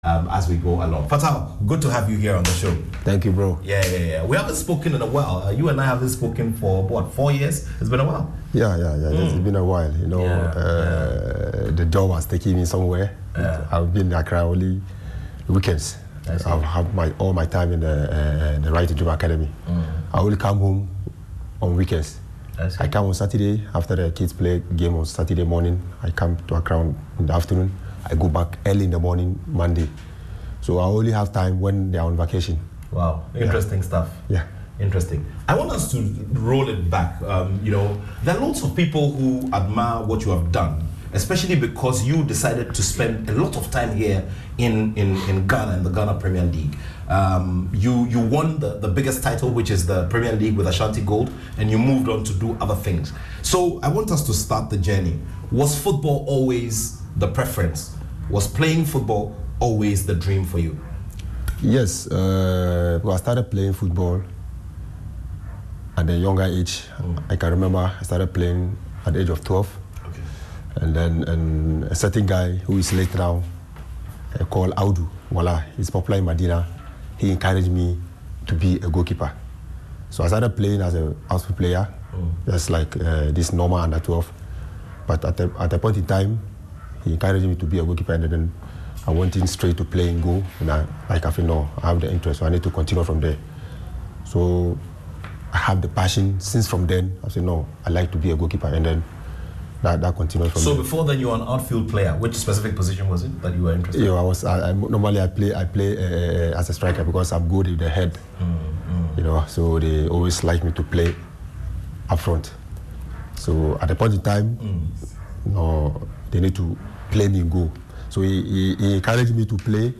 A sports discussion show on Joy FM about big-name personalities and key issues in sports